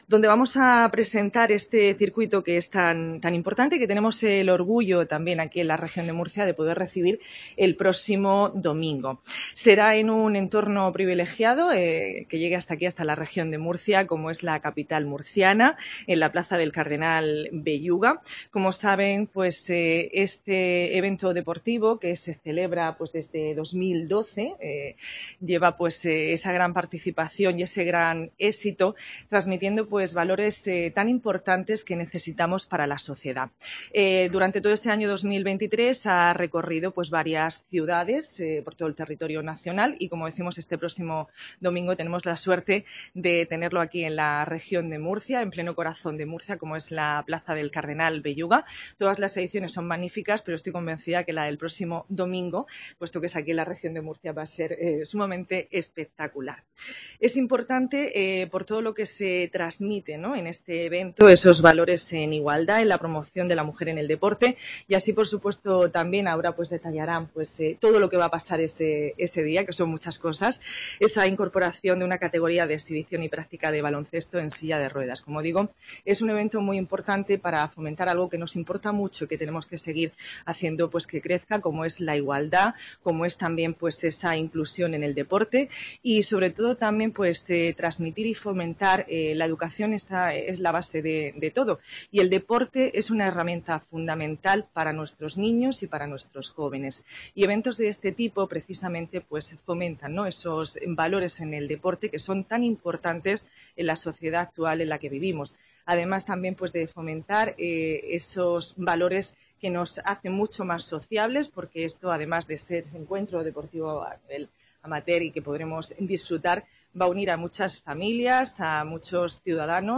Carmen María Conesa, consejera de Cultura, Turismo y Deportes